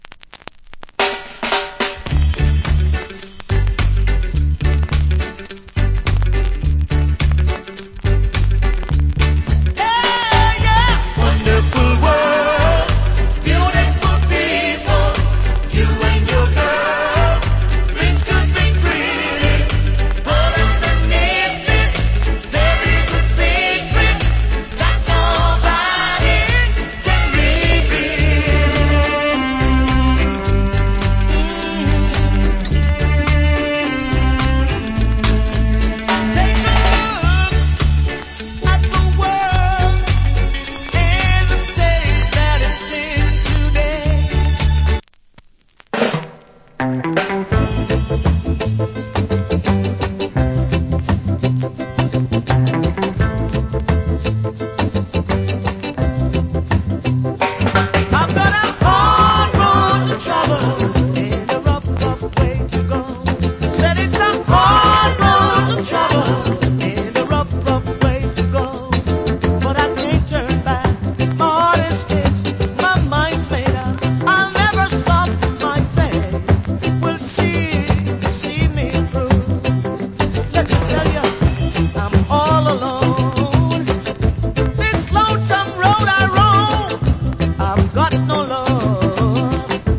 Old Hits Early Reggae Vocal.